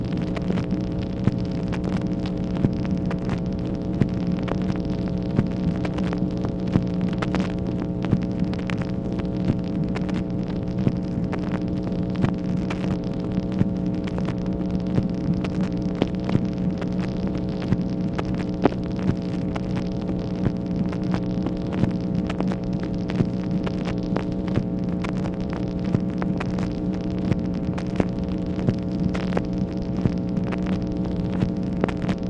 MACHINE NOISE, October 23, 1964
Secret White House Tapes | Lyndon B. Johnson Presidency